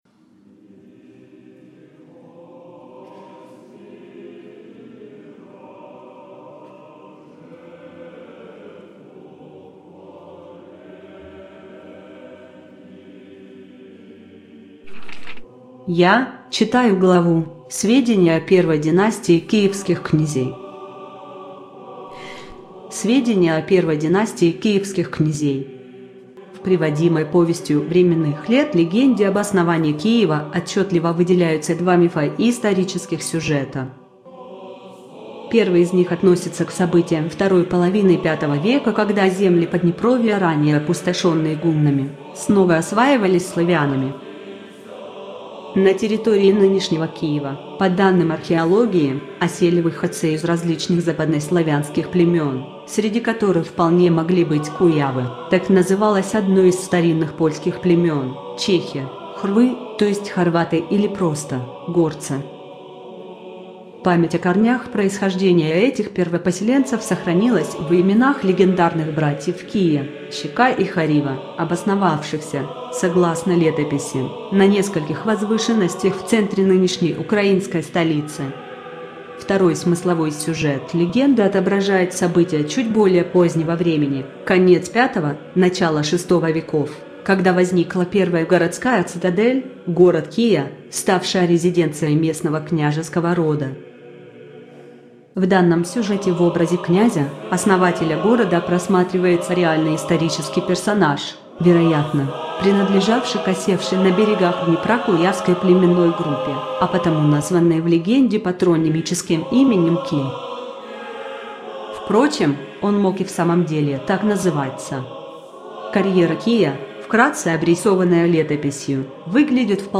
Аудиокнига : Иоакимовская летопись